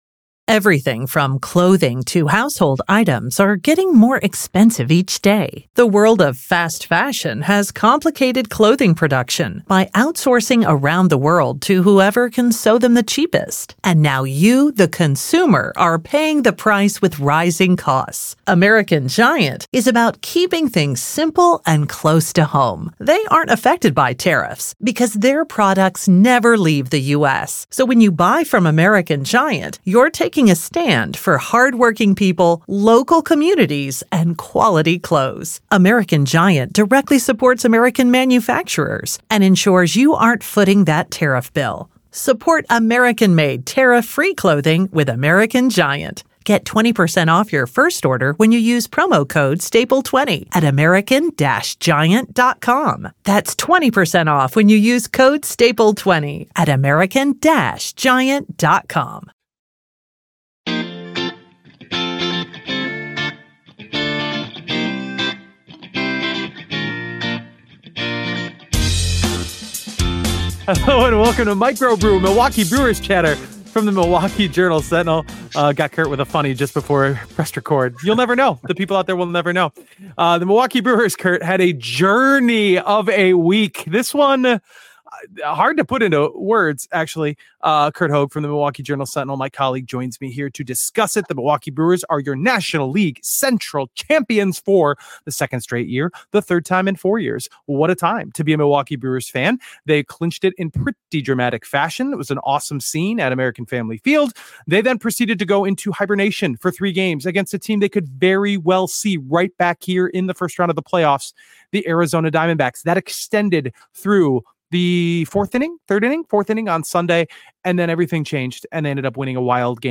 In Three Up Three Down, we have scenes from Wednesday's champagne celebration and Sunday's wild comeback.